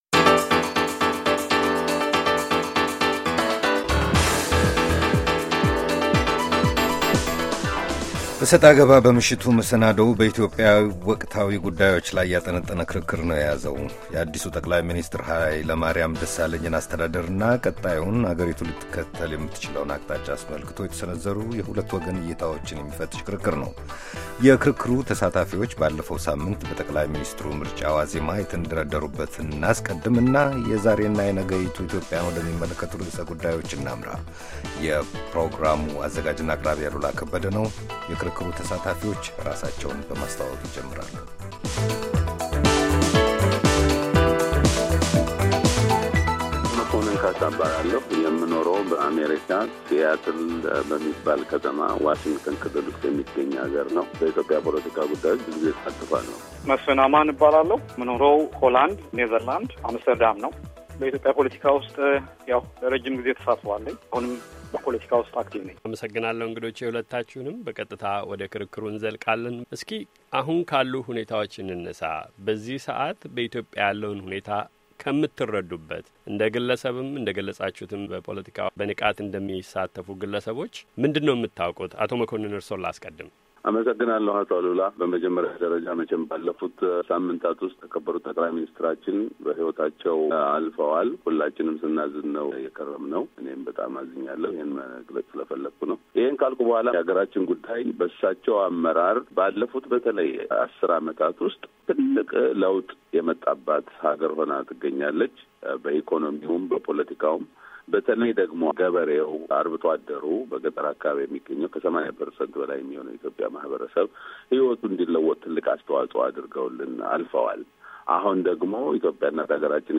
ክርክር፥ በኢትዮጵያ ወቅታዊ ጉዳዮች ዙሪያ፤